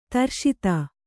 ♪ tarṣita